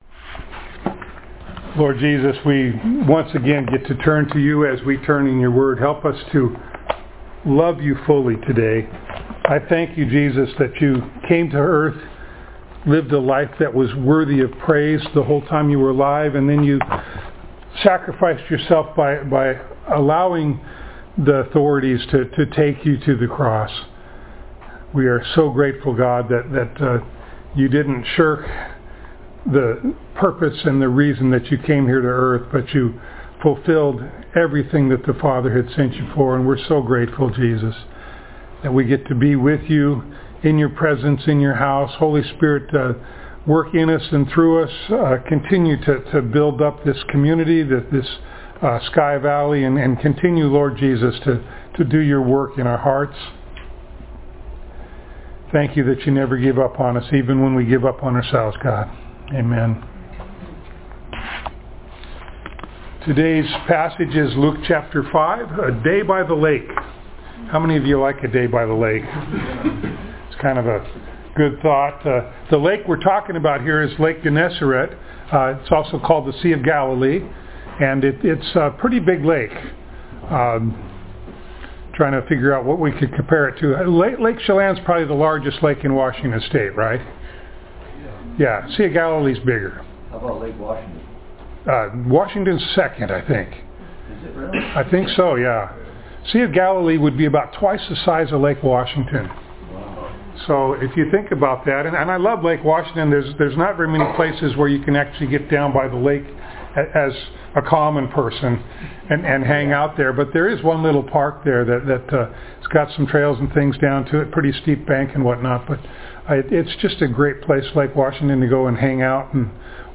Luke Passage: Luke 5:1-11 Service Type: Sunday Morning Download Files Notes « Healing